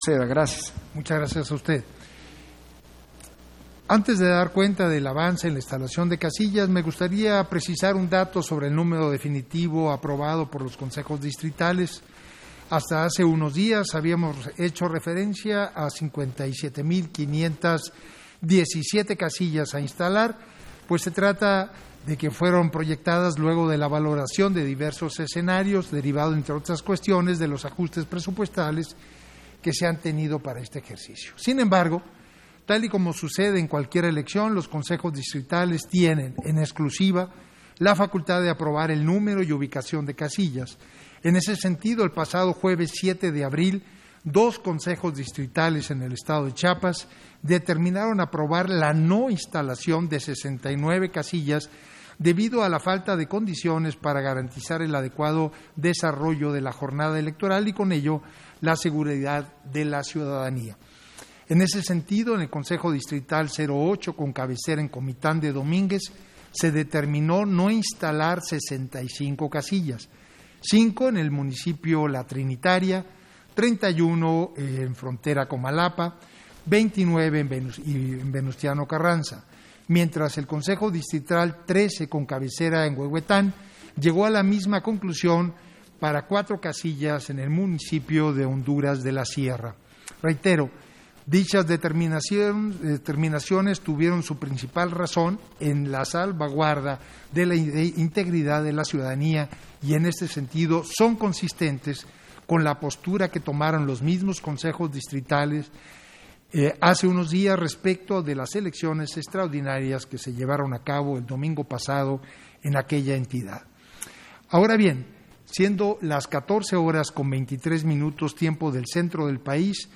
«DR0000_5054.mp3» de TASCAM DR-05.
Intervención de Edmundo Jacobo Molina, en el punto 3 de la Sesión Extraordinaria, relativo al informe de la instalación de mesas directivas de casilla, en el ejercicio de Revocación de Mandato